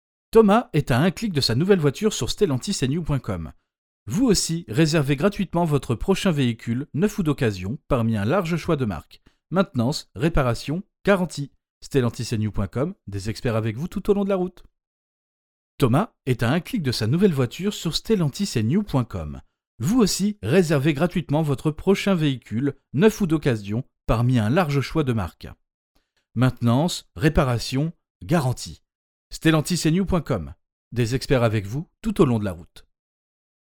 Stellantis Audition
25 - 50 ans - Baryton